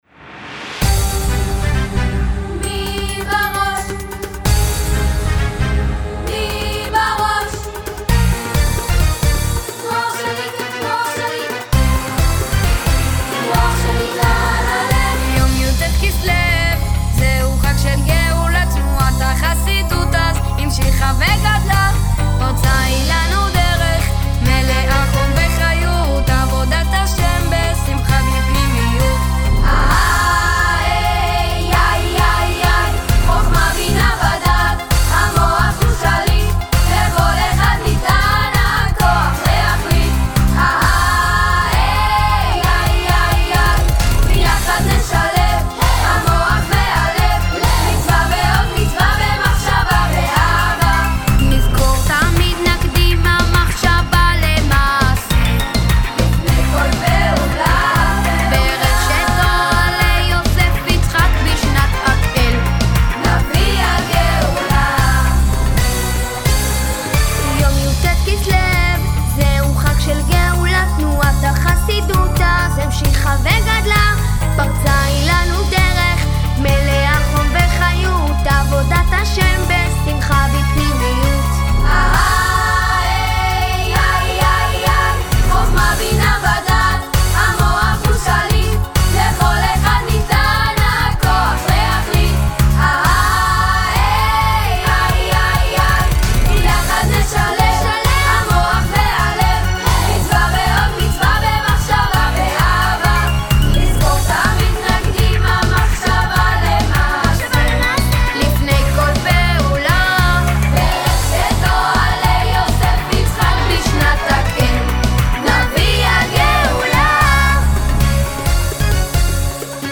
שיר